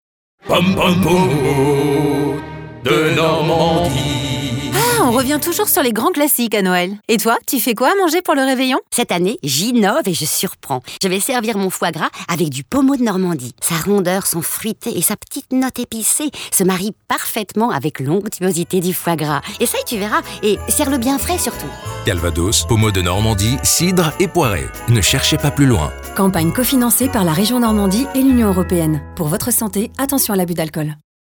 Une campagne radio pour soutenir la filière
IDAC SPOT E - ACCORD PARFAIT POMMEAU.mp3